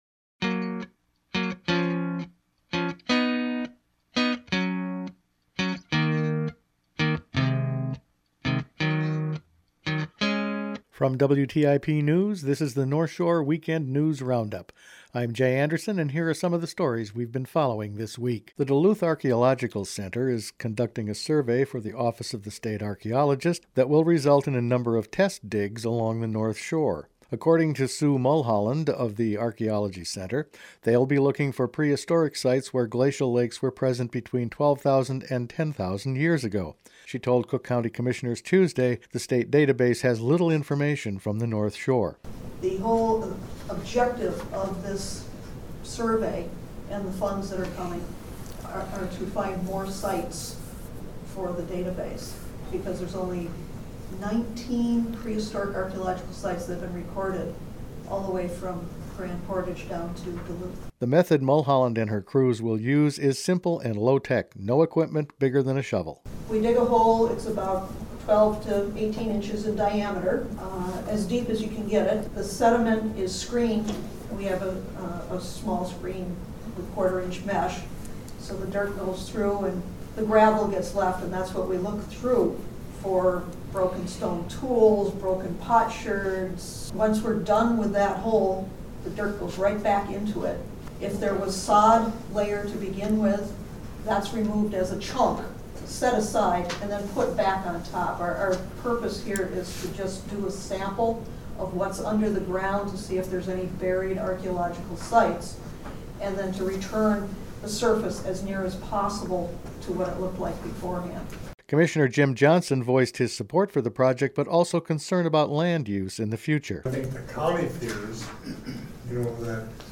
Weekend News Roundup for Oct. 23
Each weekend WTIP news produces a round up of the news stories they’ve been following this week. Archeology on the shore, Superior National Golf Course and a soil survey are some of the stories.